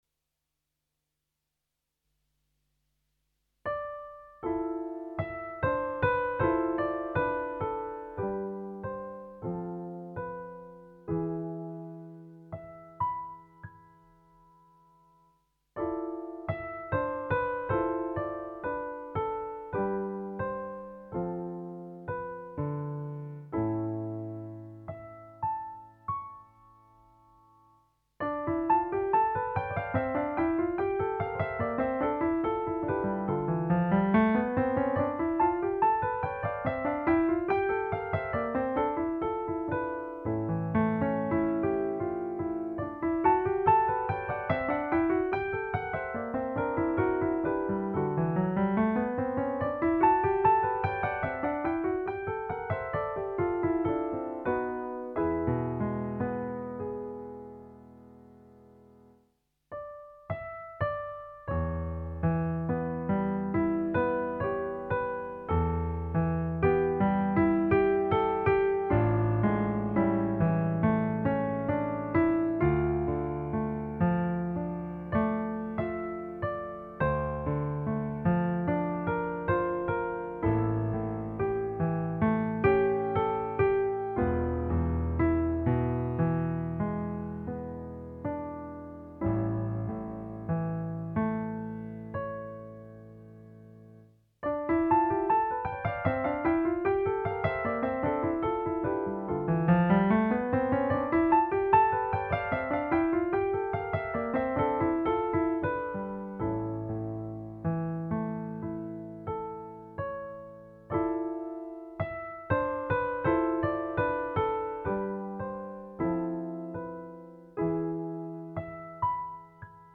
Instrumentation:Piano Solo
These short pieces are written in the romantic tradition